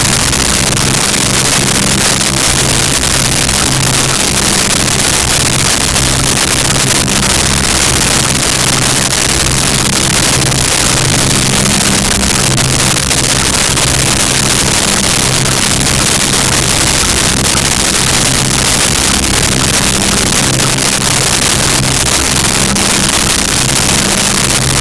Clock
CLOCK.wav